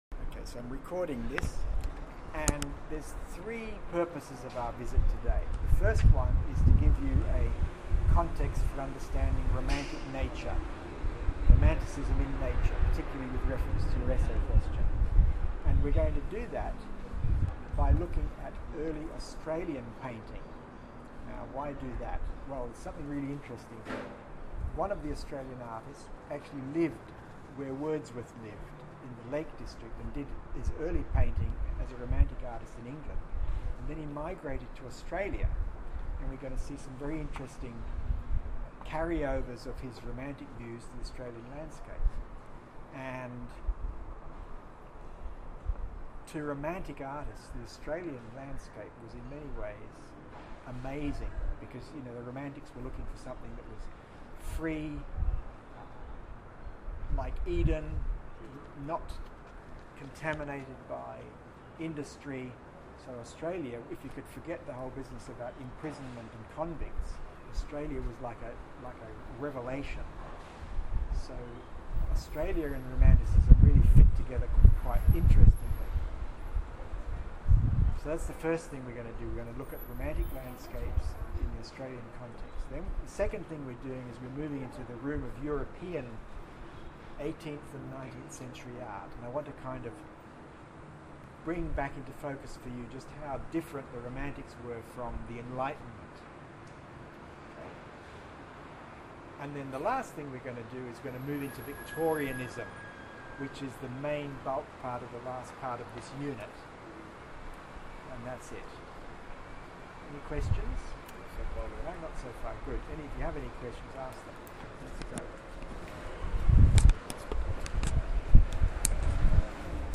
The Art Gallery of NSW with Nineteenth Century Literature Students.